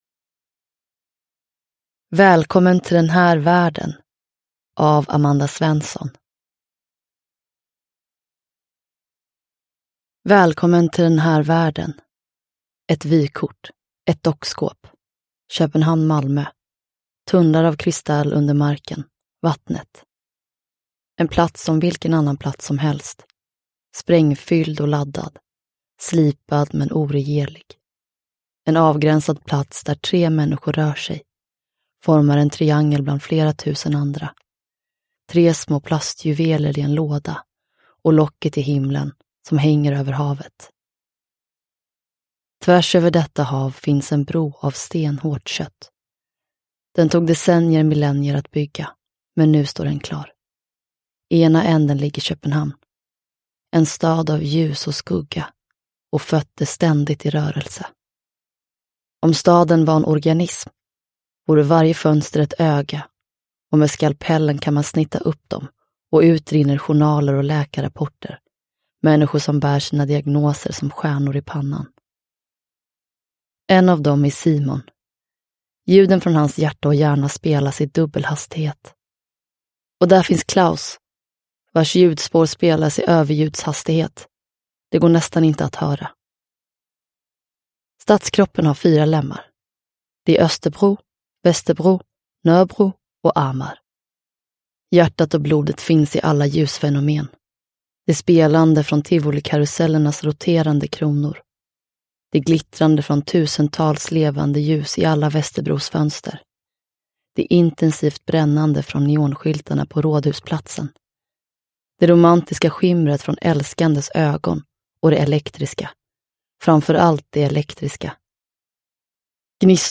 Välkommen till den här världen – Ljudbok – Laddas ner